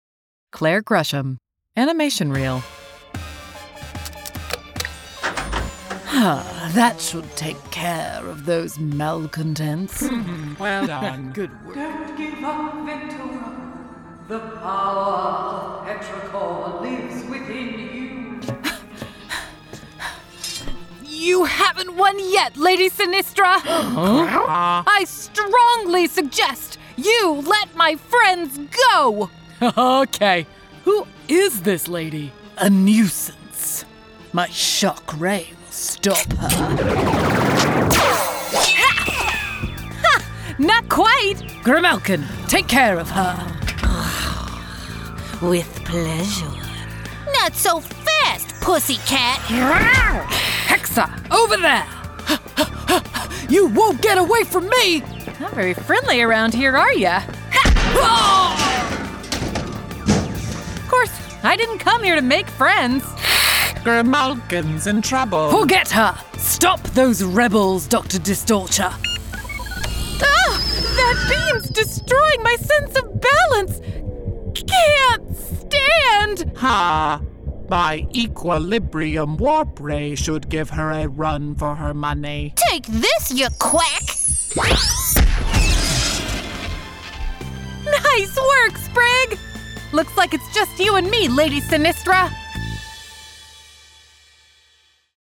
Animation Multi-Character Reel
General American, US Southern (various dialects), British RP
Middle Aged